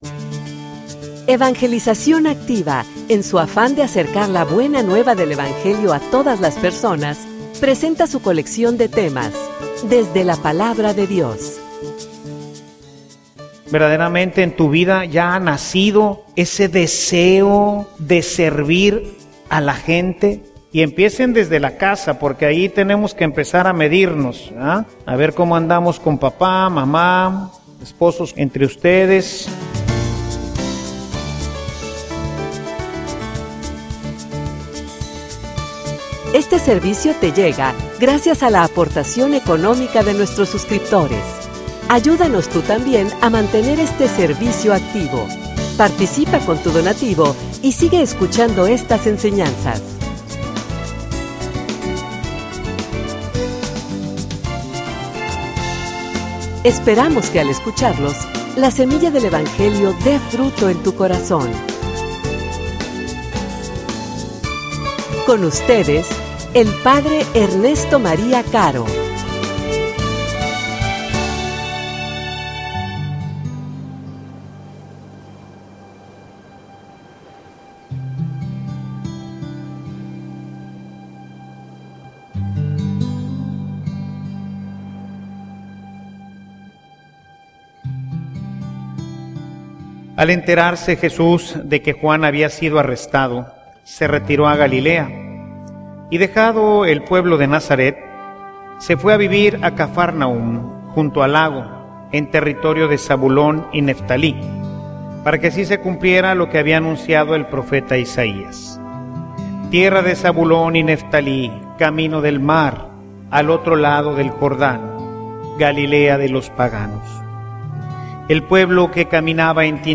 homilia_Conversion_y_seguimiento_de_Cristo.mp3